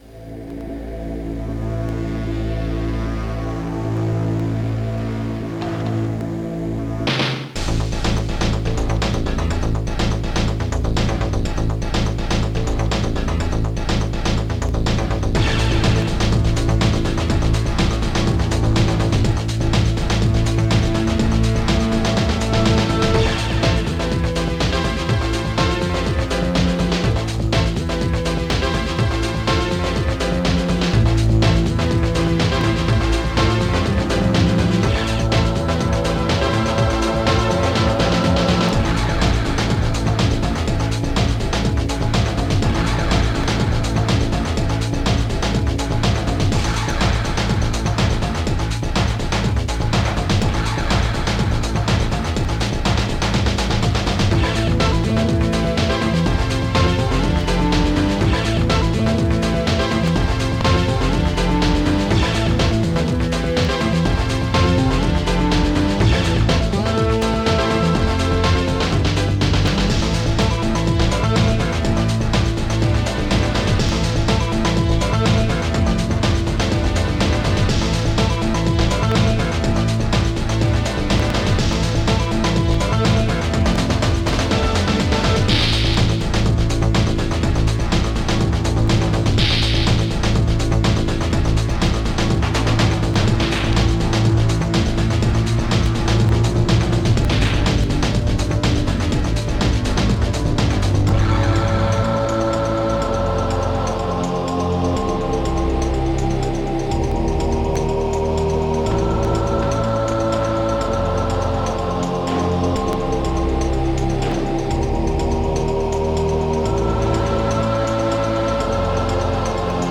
Music: tracker 8-channel .s3m